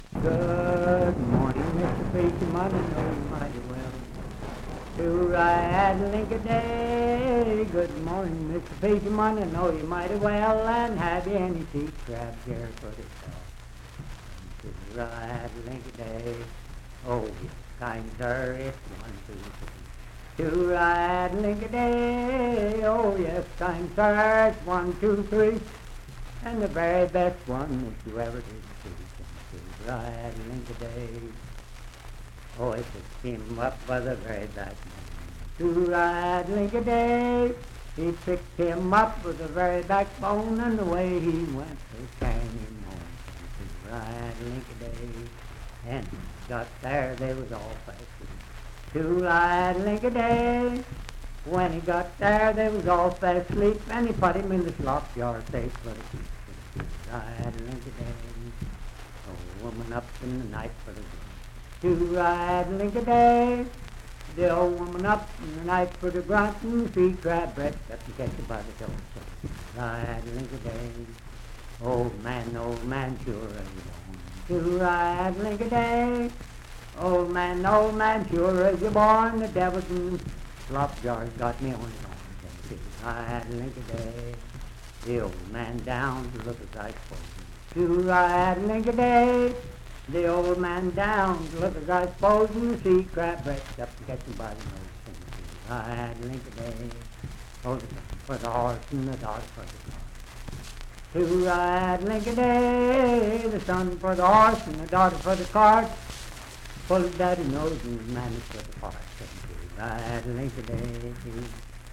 Unaccompanied vocal music
Verse-refrain 8(5w/R).
Bawdy Songs
Voice (sung)
Parkersburg (W. Va.), Wood County (W. Va.)